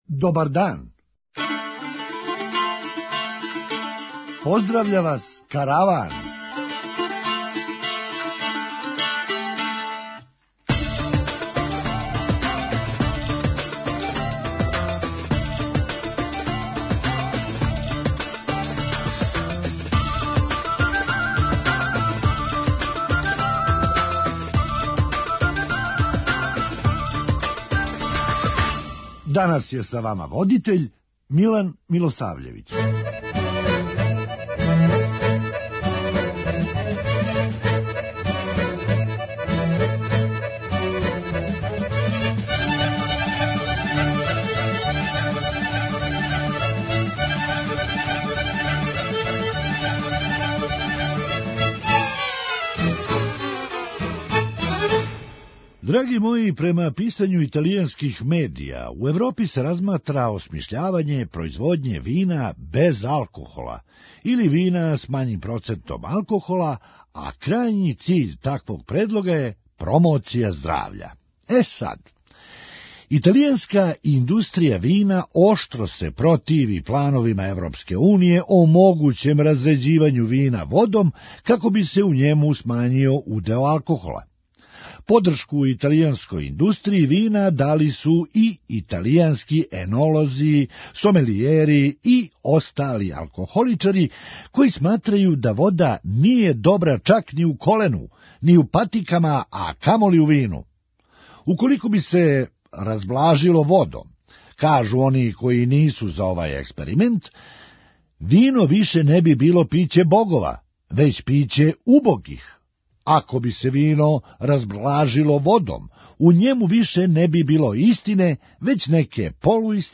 Караван: Хумористичка емисија